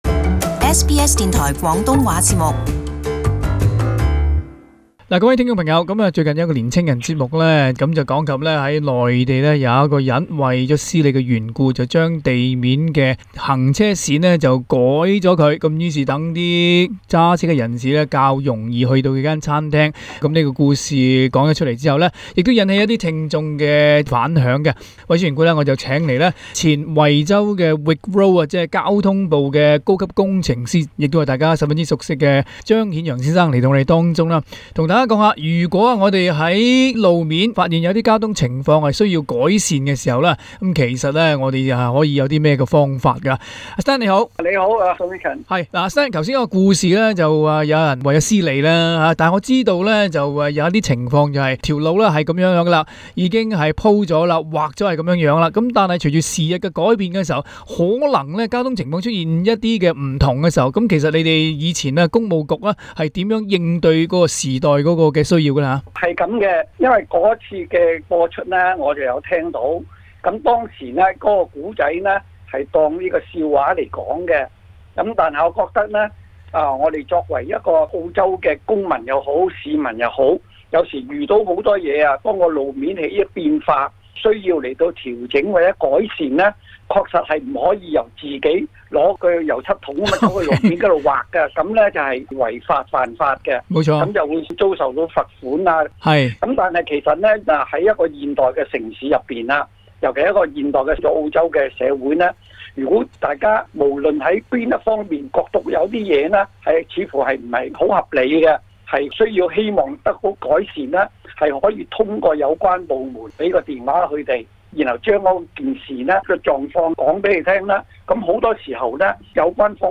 【社區專訪】修橋整路 有法可依